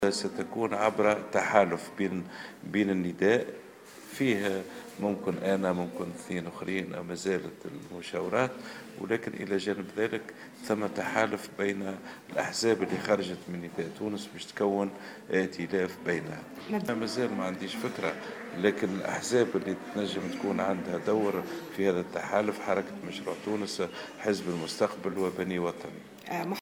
وقال في تصريحات على هامش اجتماع لمجموعة من الأحزاب مساء اليوم بسوسة إن الأحزاب التي يمكن ان يكون لها دور في هذا التحالف هي مشروع تونس وحزب المستقبل وبني وطني.